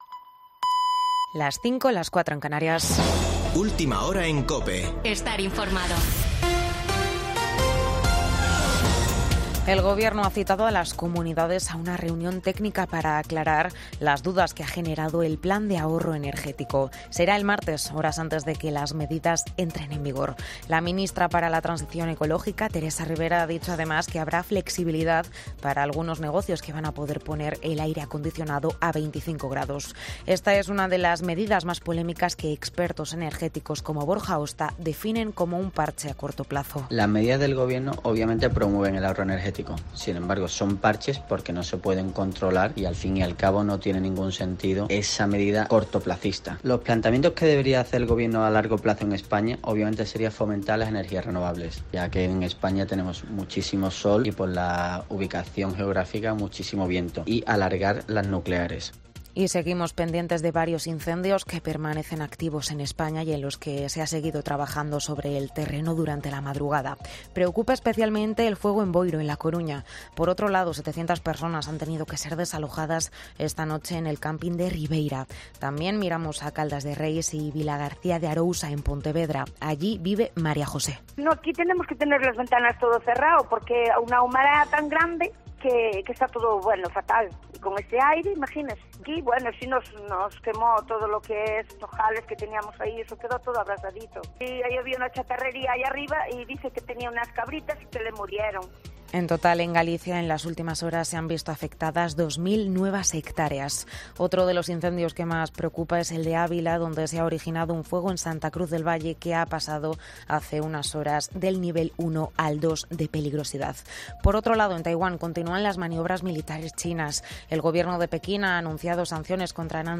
AUDIO: Boletín de noticias de COPE del 6 de agosto de 2022 a las 05.00 horas